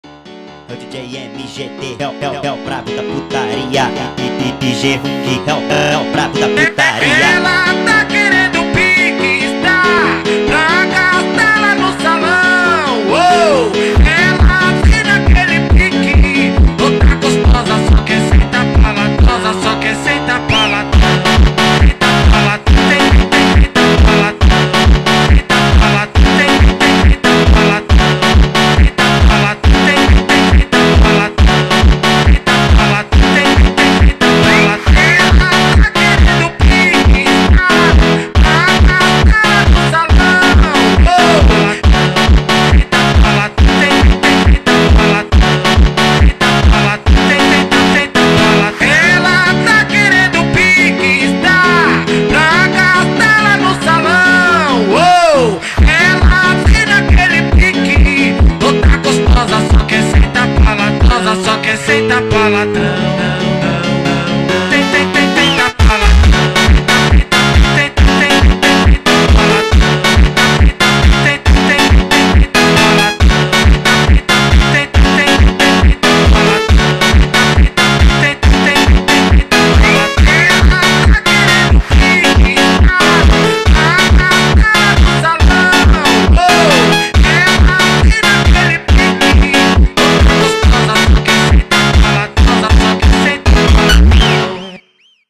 فانک